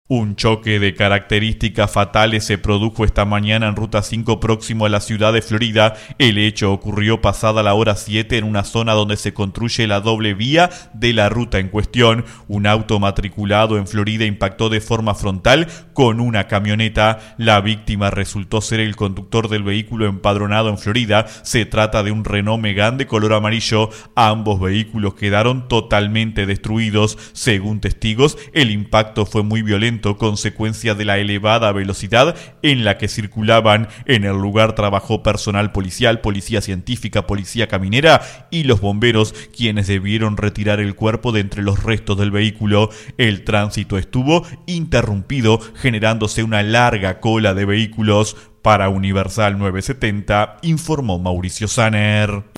Corresponsal para 970 Noticias